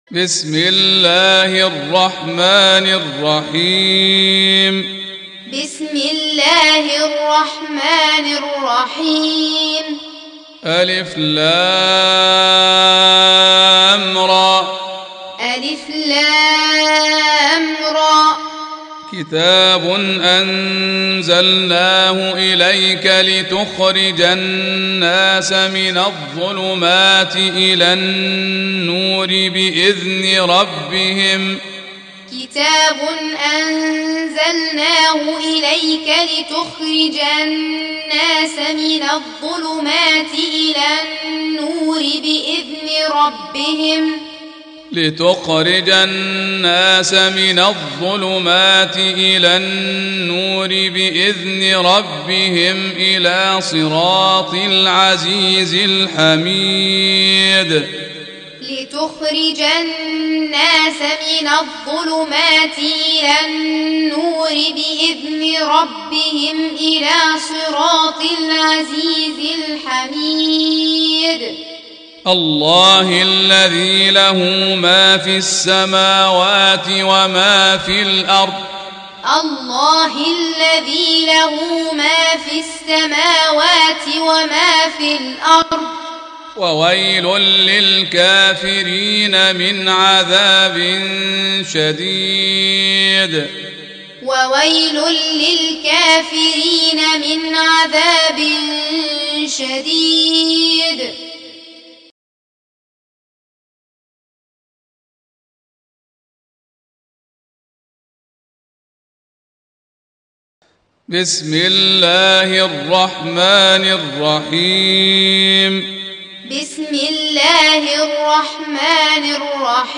14. Surah Ibrah�m سورة إبراهيم Audio Quran Taaleem Tutorial Recitation
Surah Sequence تتابع السورة Download Surah حمّل السورة Reciting Muallamah Tutorial Audio for 14.